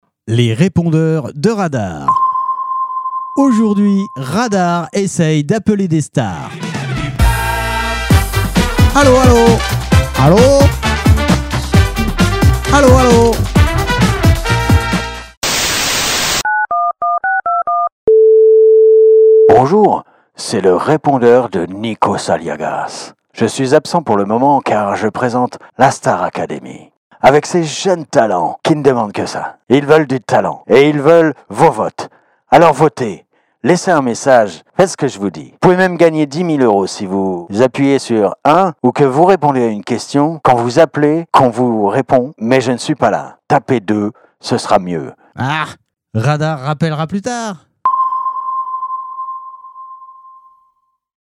Les répondeurs de Radar parodies répondeurs stars radar